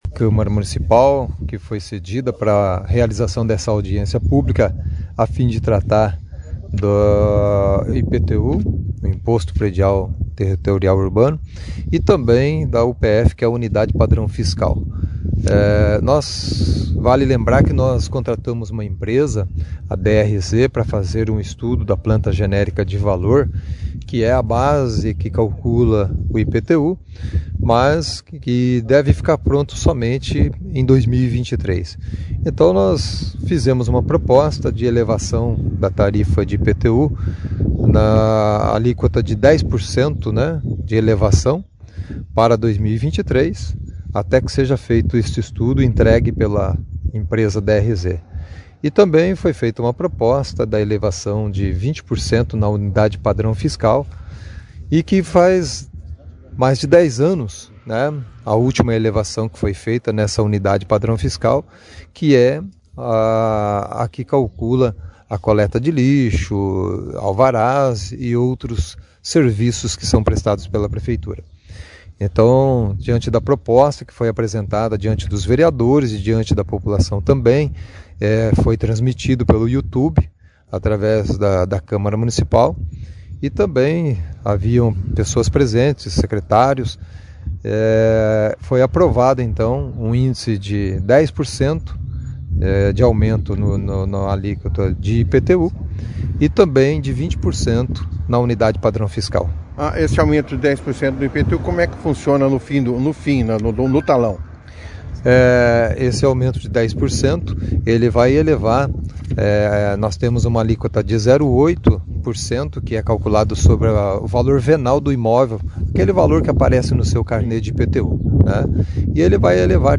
O Prefeito Jaelson da Matta, participou da 2ª edição do Jornal Operação Cidade desta quinta-feira, 17/11, falando sobre a audiência.